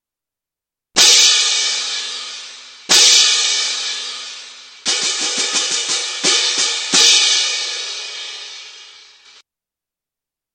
platillos.mp3